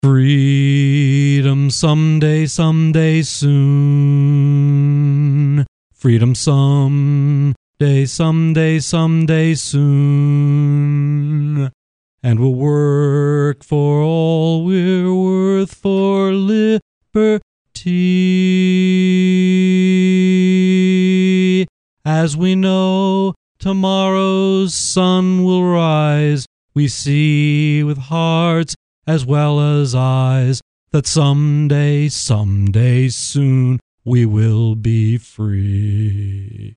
I sang it alone last night, and it didn’t sound too bad to me — but then, as H. L. Mencken pointed out, a man always believes that his wife is pretty and his children are intelligent . . .